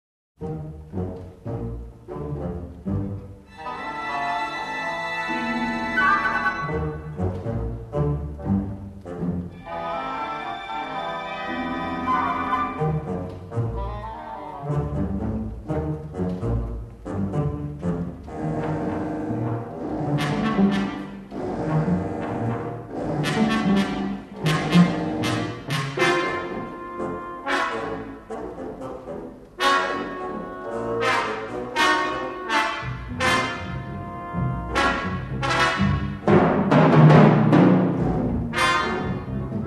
sole surviving mono mixdown safety master